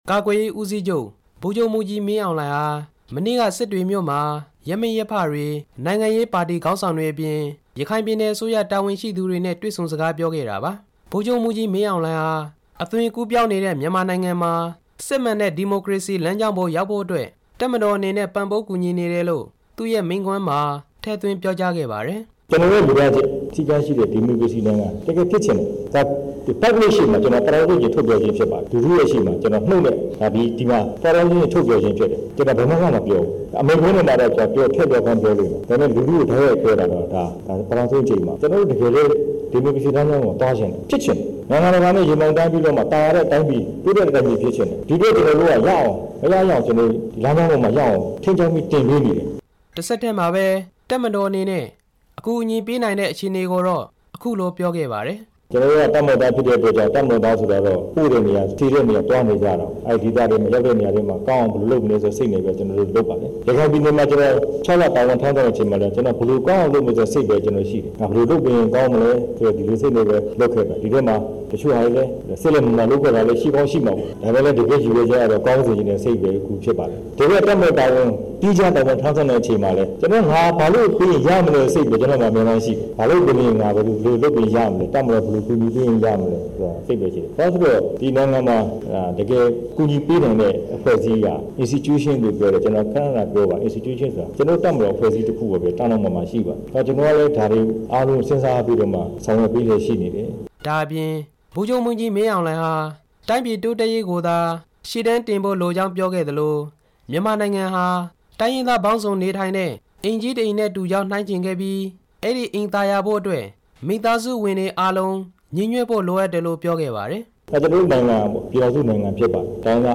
ဗိုလ်ချုပ်မှူးကြီး မင်းအောင်လှိုင်ရဲ့မိန့်ခွန်းနဲ့ တက်ရောက်ခဲ့တဲ့သူတွေ အမြင်
ရခိုင်ပြည်နယ် စစ်တွေမြို့ ပြည်နယ်အစိုးရအဖွဲ့ရုံးမှာ ဒီကနေ့ ဒီဇင်ဘာလ ၃ ရက်နေ့ ညနေပိုင်း မှာ ပြည်နယ်အစိုးရတာဝန်ရှိသူတွေ၊ ရပ်မိရပ်ဖတွေနဲ့ တွေ့ဆုံစဉ် ဗိုလ်ချုပ်မှူးကြီး မင်းအောင်လှိုင်က အခုလို ပြောကြားလိုက် တာဖြစ်ပါတယ်။